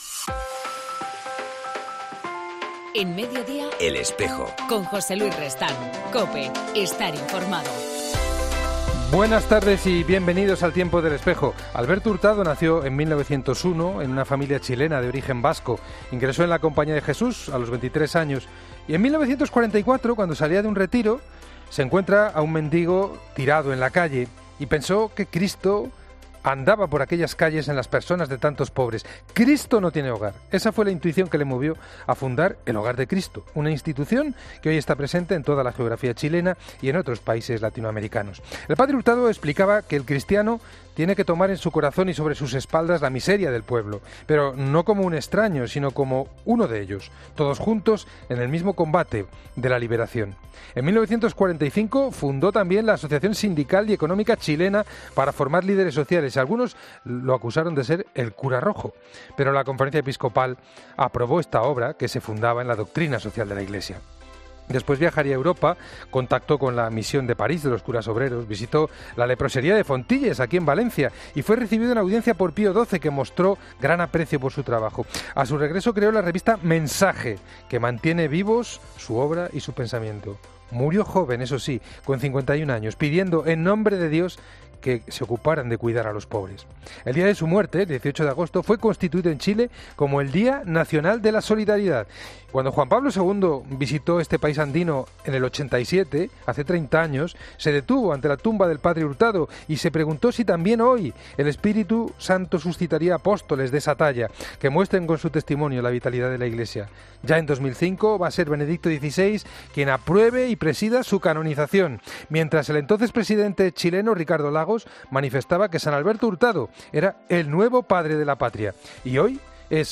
En El Espejo del 18 de enero entrevistamos a Adolfo Glez. Montes, Obispo de Almería